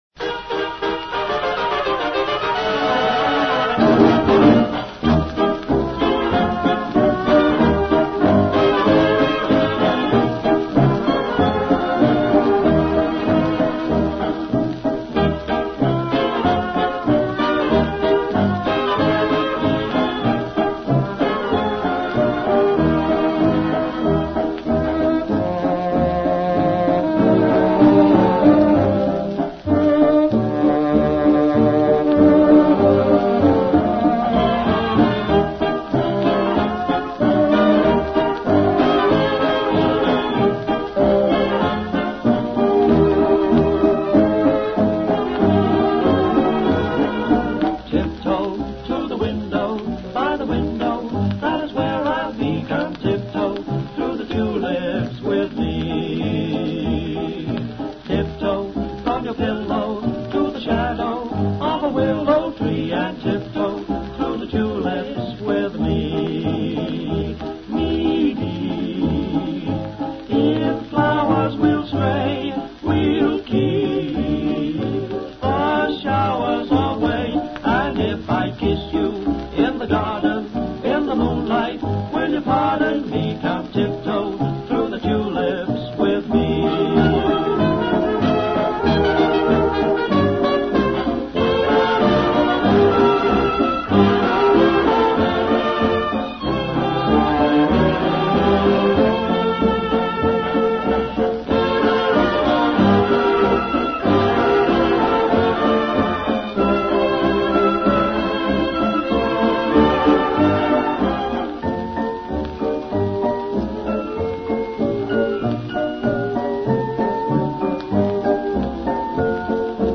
Tanzmusik wurde nun von Big Bands ins ganze Land übertragen.